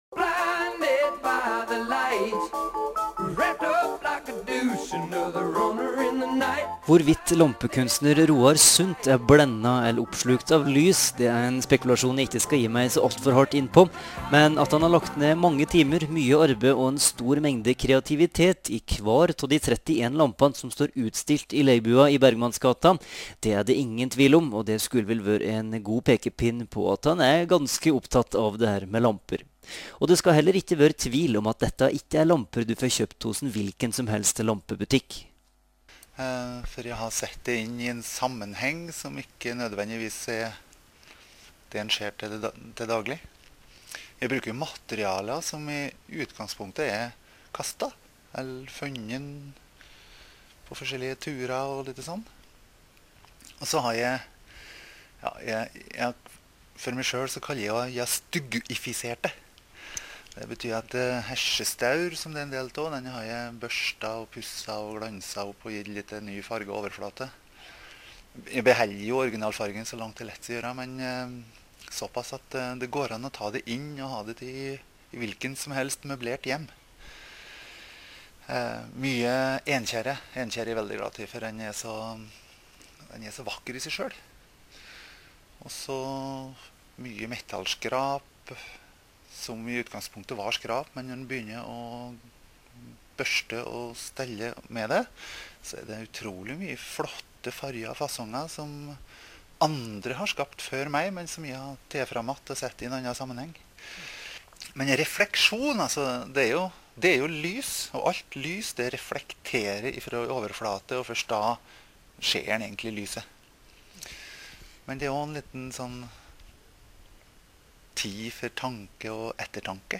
Adresseavisen 22. november 2008 (pdf dokument) Arbeidets Rett 21. november 2008 (pdf dokument) Arbeidets Rett - "På tråden" (pdf dokument) Nea Radio radiointervju (WMA fil 9 MB) NRK Trøndelag radiointervju (WMA fil 1,8 MB)